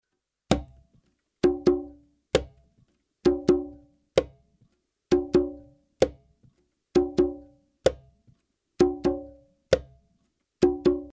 130 BPM conga loops (15 variations)
Real conga loops played by professional percussion player at 130 BPM.
The conga loops were recorded using 3 microphones,
(AKG C-12 VR , 2 x AKG 451B for room and stereo).
All the loops are dry with no reverb , light EQ and compression , giving you the Opportunity to shape the conga loops in your own style and effect. The conga loops are for salsa beat, will work great If you are composing a latin salsa song.
*- room reverb was added to the conga loops in the preview.